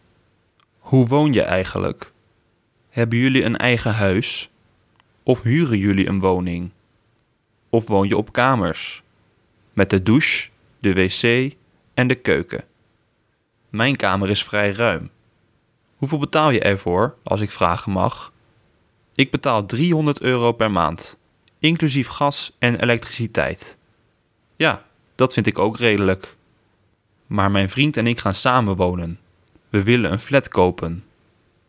Przesłuchaj zdania wypowiedziane przez holenderskiego native speakera i przekonaj się sam!